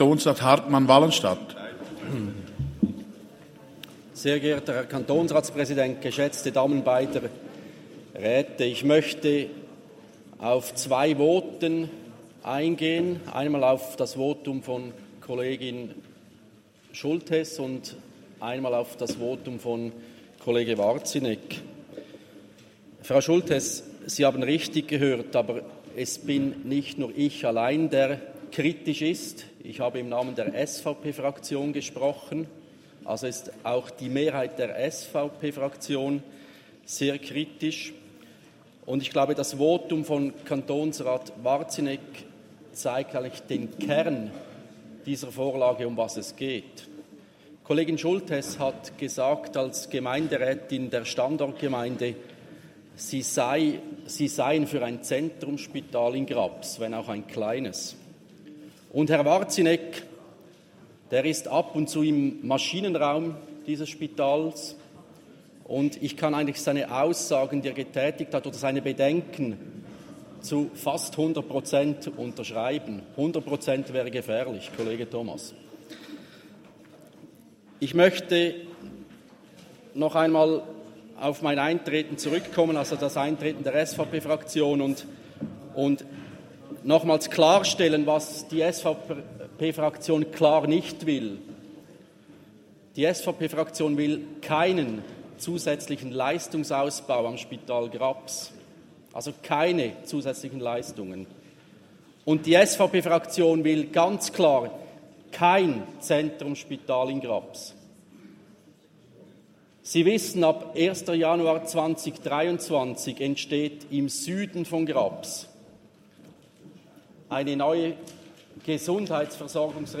30.11.2022Wortmeldung
Session des Kantonsrates vom 28. bis 30. November 2022